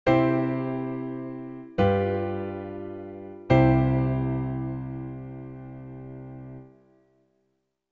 Bowing chord #1 (sound effects)
Bridge
Bowing sign sound effect on the piano....